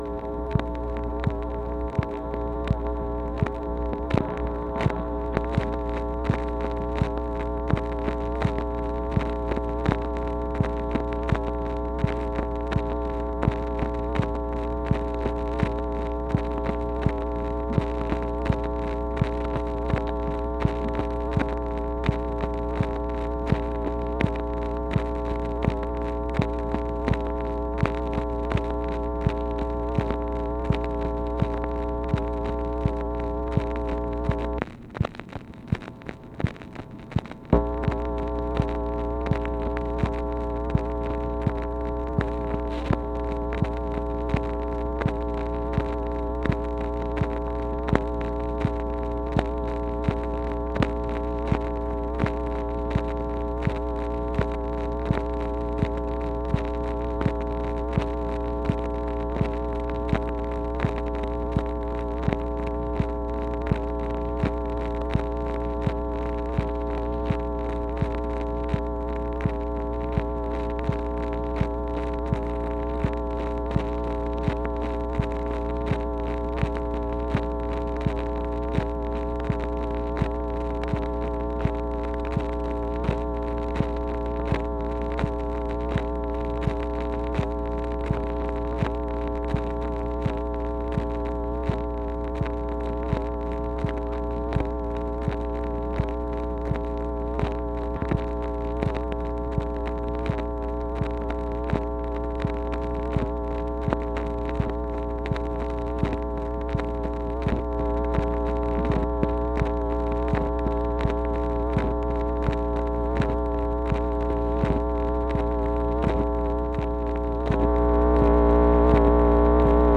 MACHINE NOISE, November 9, 1965